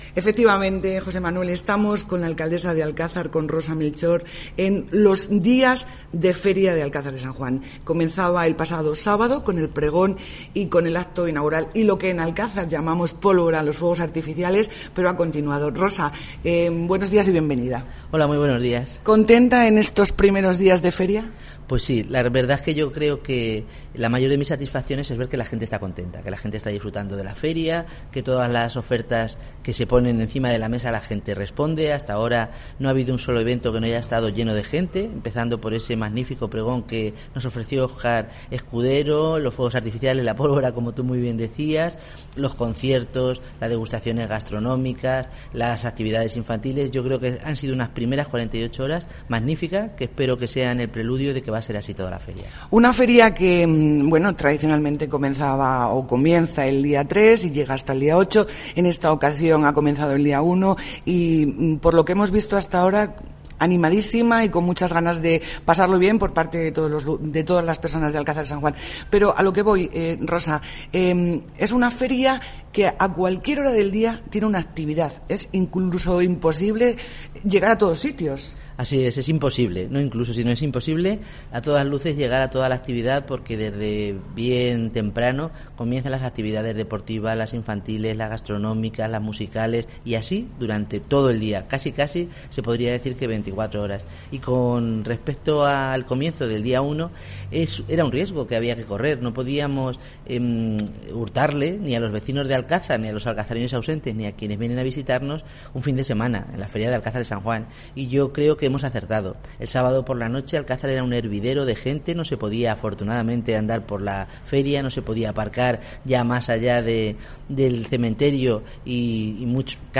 Alcázar de San Juan se encuentra en plena de celebración de sus fiestas. Hablamos con la alcaldesa, Rosa Melchor.